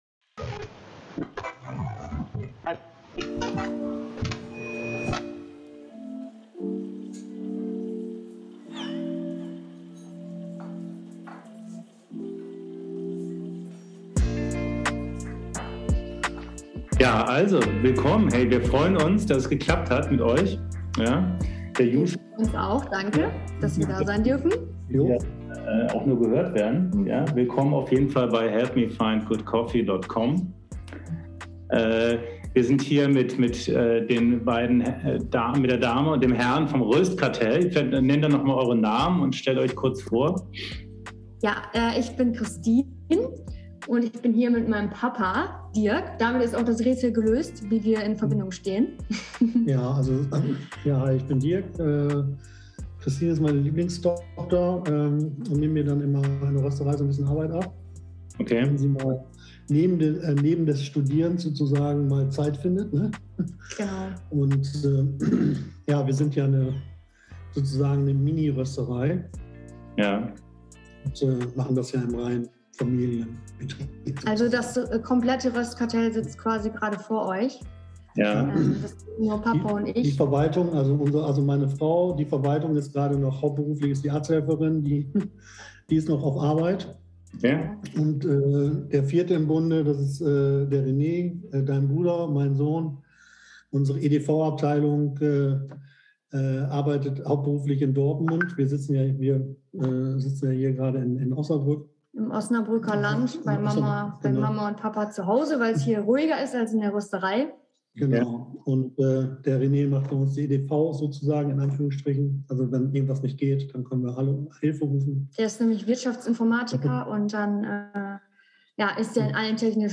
Folge 10: Interview mit dem Röstkartell